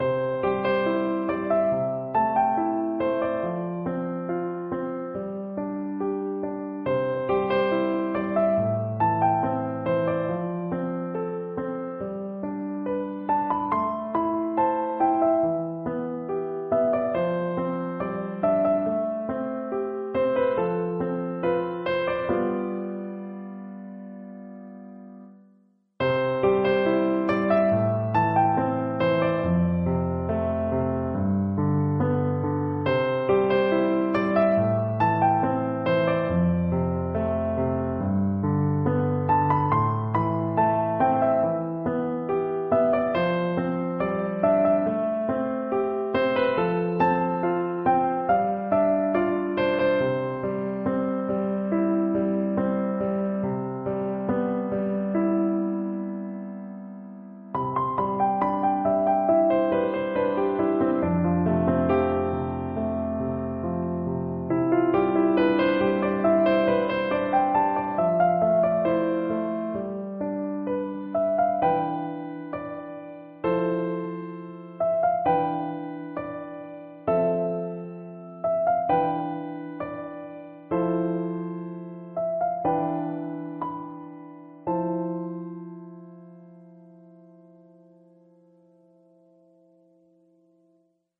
(gamerip)